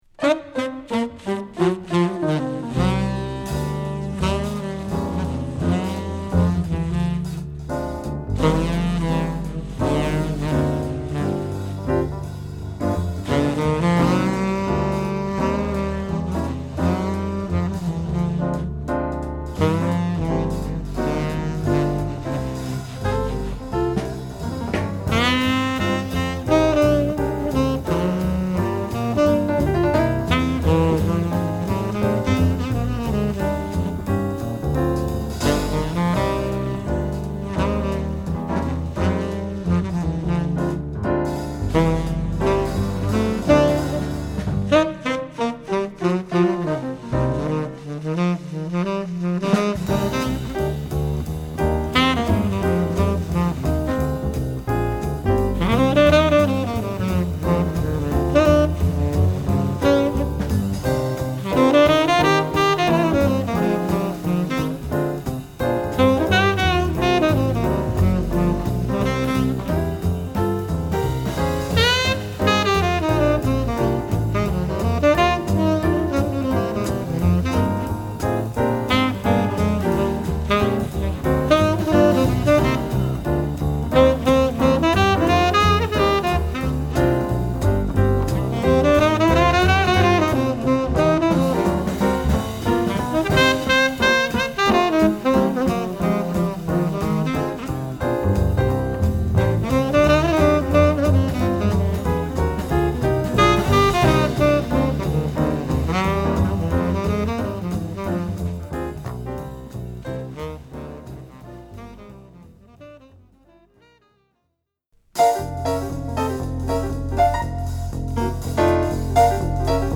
NYとサンフランシスコの2箇所で録音。